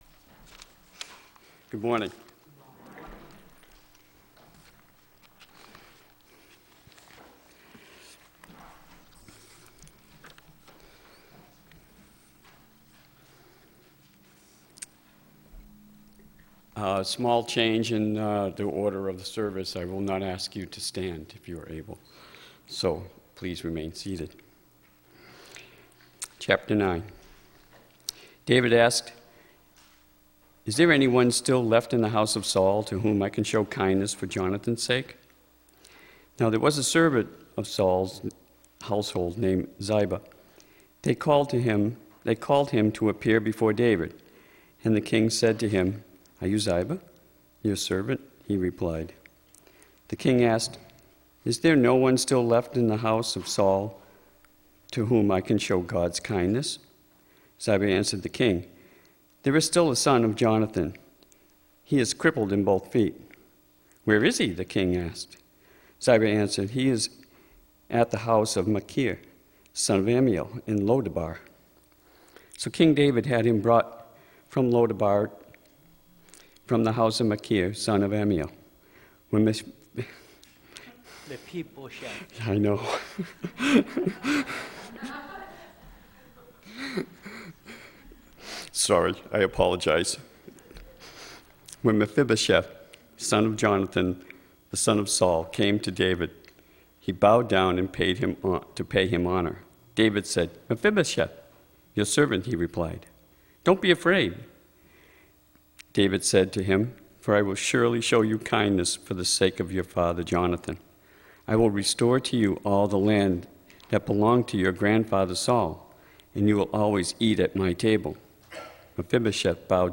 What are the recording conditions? Audio Recording of Oct. 25th Worship Service – Now Available The audio recording of our latest Worship Service is now available.